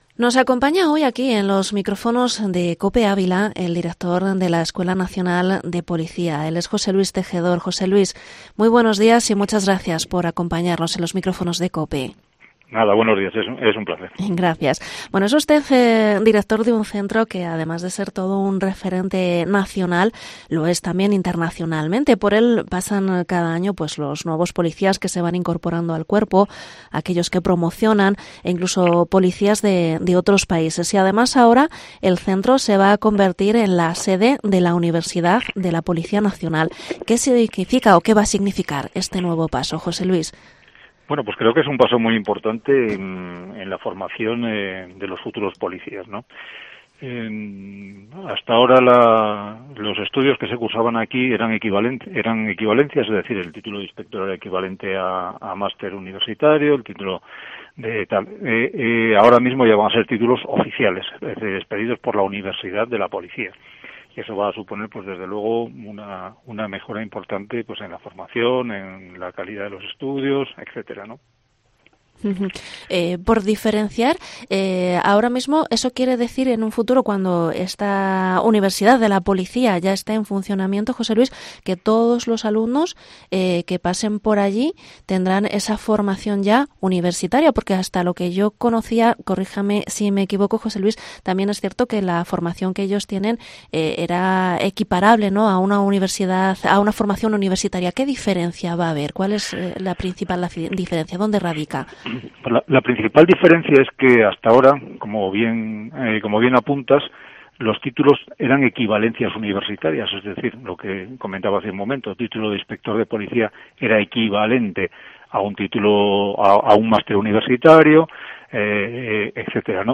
Entrevista en COPE ÁVILA al director de la Escuela Nacional de Policía, José Luís Tejedor